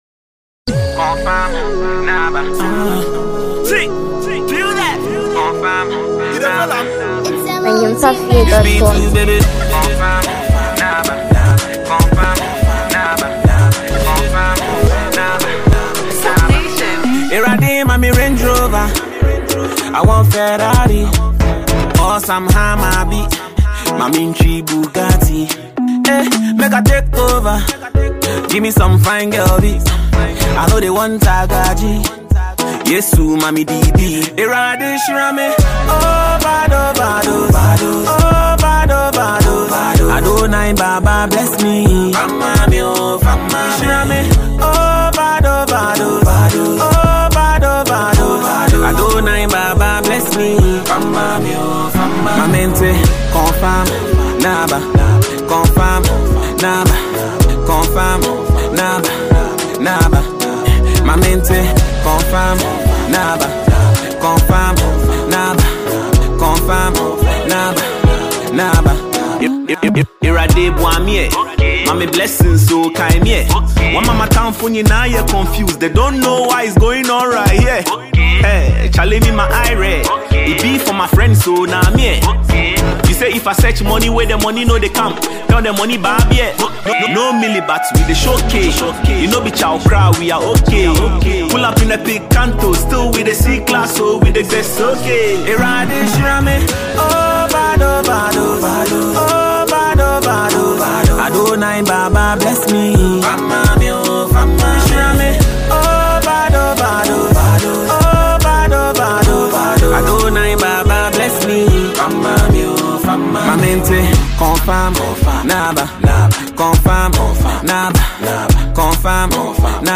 afrobeat duo
brand new afrobeat collaboration
amazing and groovy song